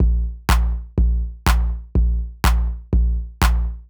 ENE Beat - Kick _ Clap.wav